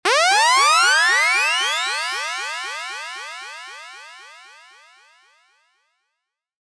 Laser Delays 26
Laser-Delays-26.mp3